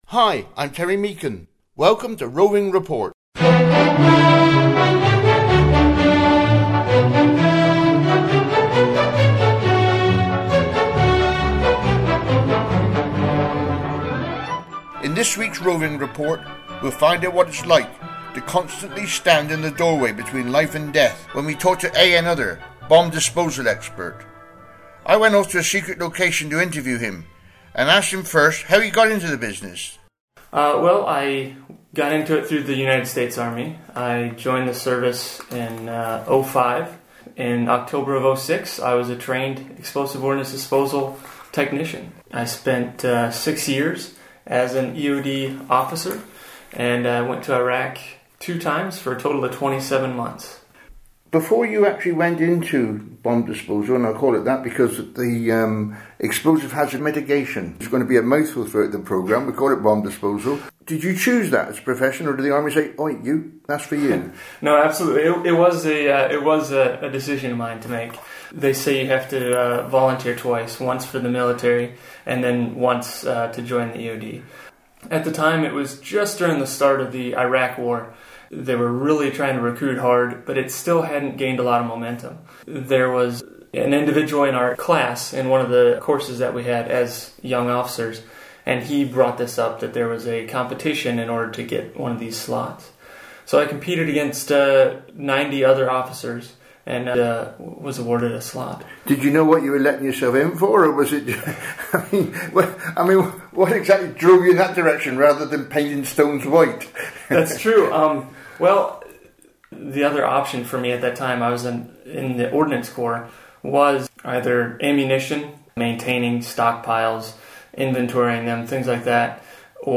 Interview with a Bomb Disposal Expert
⏱ Running time: 27 minutes Originally Broadcast on Regional Radio.